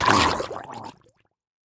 Minecraft Version Minecraft Version 1.21.5 Latest Release | Latest Snapshot 1.21.5 / assets / minecraft / sounds / mob / drowned / water / death2.ogg Compare With Compare With Latest Release | Latest Snapshot
death2.ogg